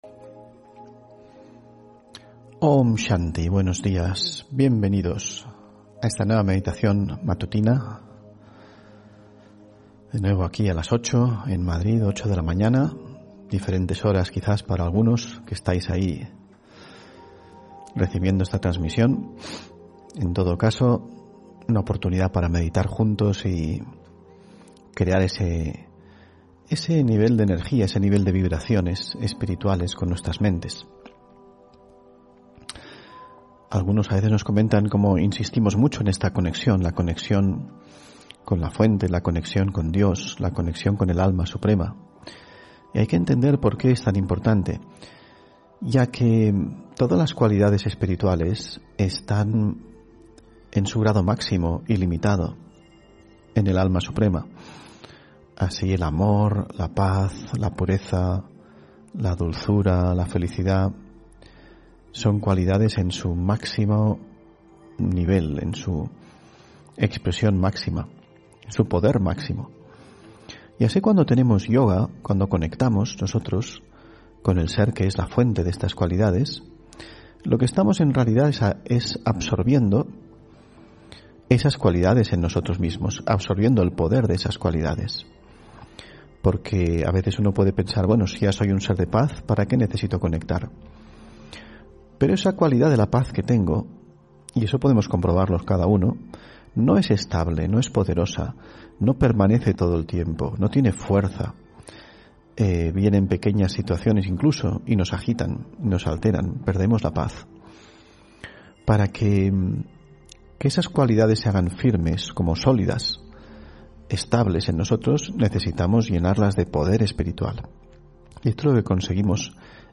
Meditación de la mañana: Recarga el alma desde el silencio